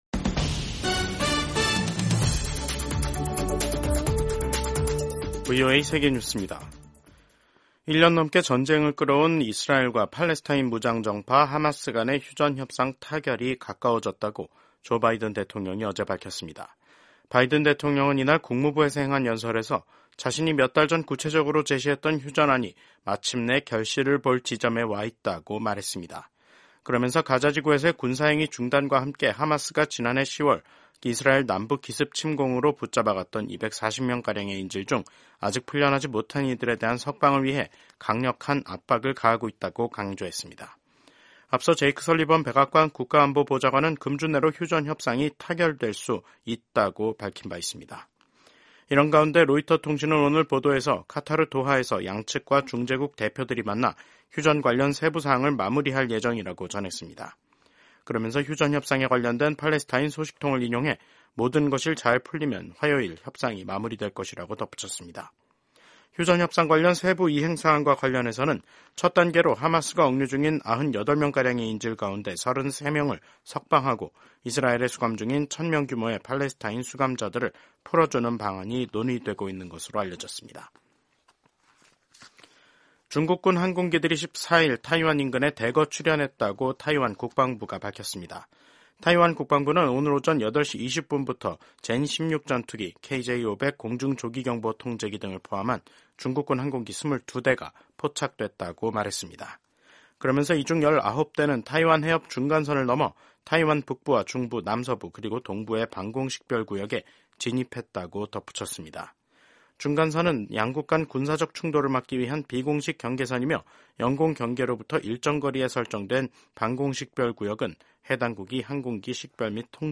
세계 뉴스와 함께 미국의 모든 것을 소개하는 '생방송 여기는 워싱턴입니다', 2025년 1월 14일 저녁 방송입니다. 차기 도널드 트럼프 내각의 인준 청문회가 14일부터 본격적으로 시작됩니다. 트럼프 당선인의 지난 2020년 대선 결과 뒤집기 혐의에 대한 특검 보고서가 공개됐습니다. 보리스 피스토리우스 독일 국방부 장관이 우크라이나를 방문해 독일의 변함없는 지원을 약속했습니다.